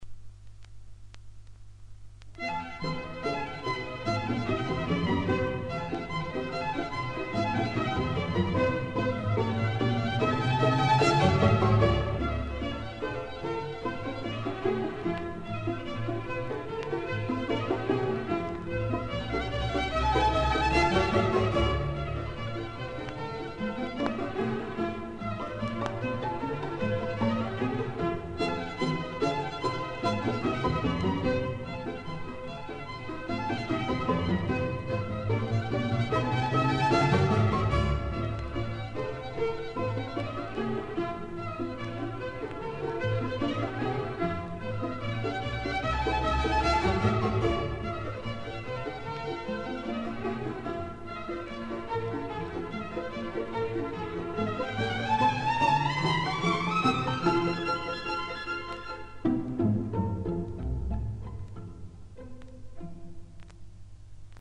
Repertoár souboru obsahuje skladby od baroka až po současnost, které odpovídají zvukovým možnostem dvanáctičlenného smyčcového orchestru, dnes však už není výjimkou jeho rozšíření o další nástroje.